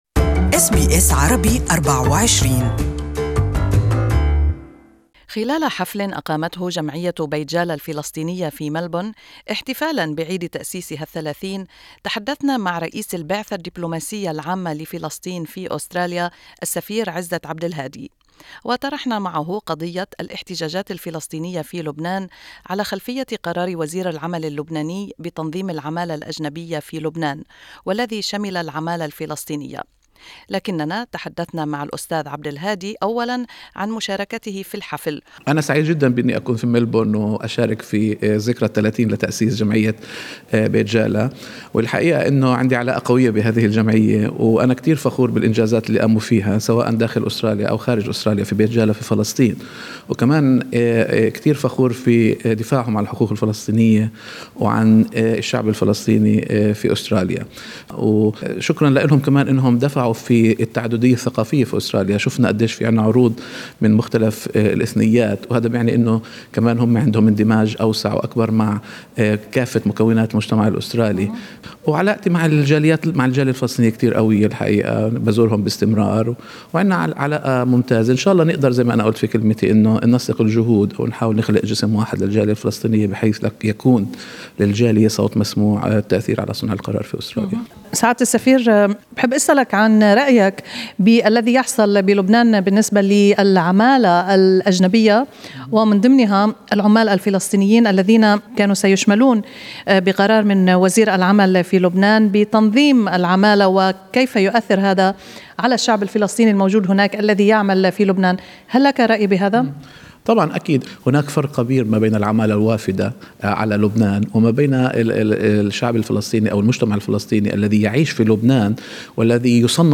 In this interview we spoke about this issue with Mr Izzat Abdulhadi, the Head of the General Delegation of Palestine to Australia, New Zealand and the Pacific.